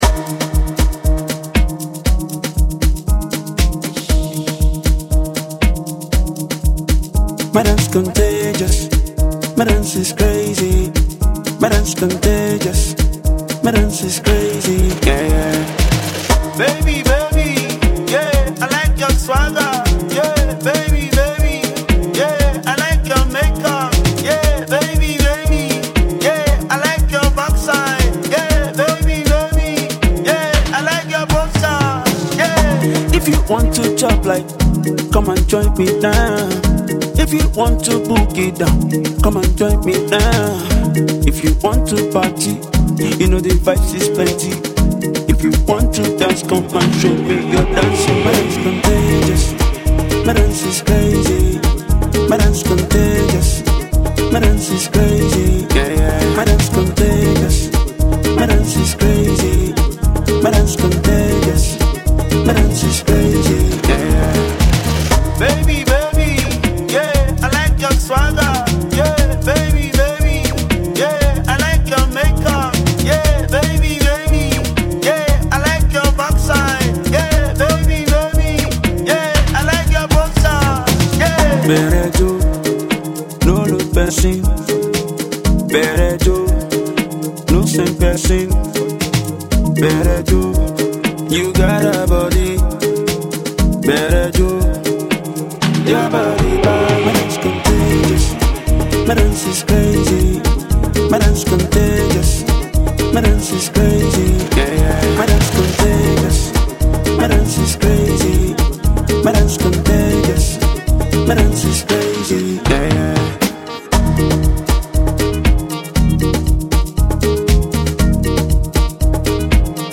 Amapiano themed dance tune